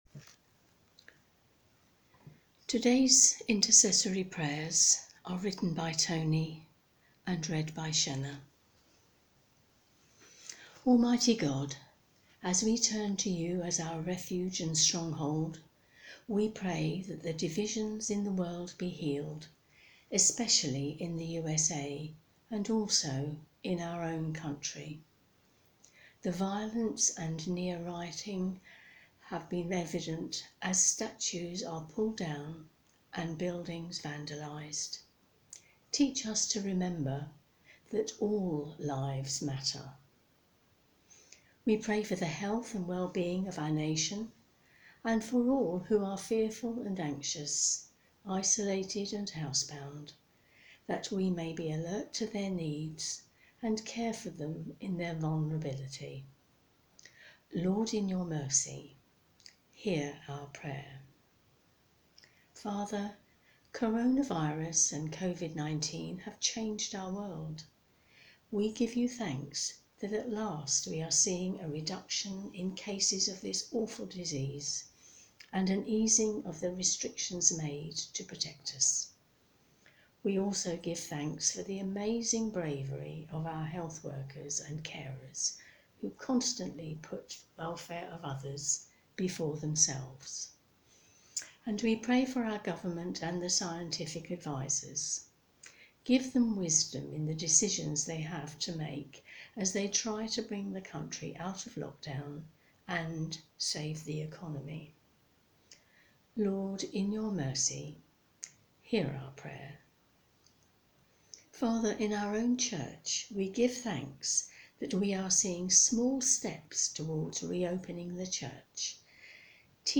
The Prayers